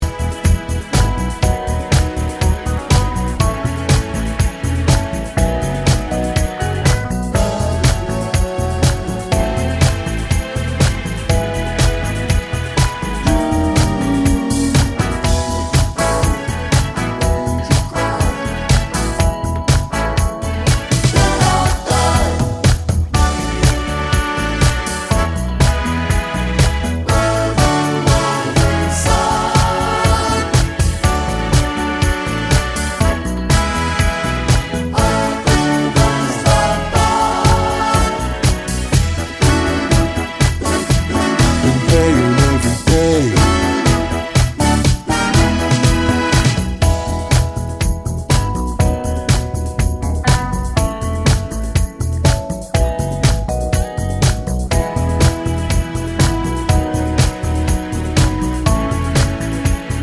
Two Semitones Down Soul / Motown 4:16 Buy £1.50